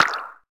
pop.wav